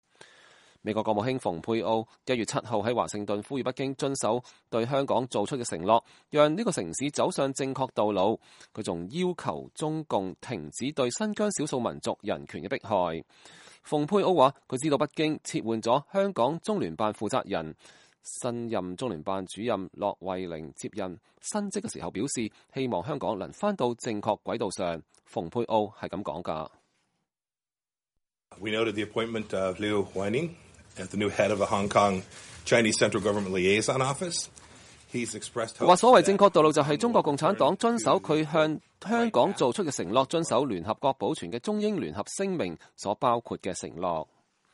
蓬佩奧在美國國務院對媒體說，他知道了北京撤換香港中聯辦負責人的事情。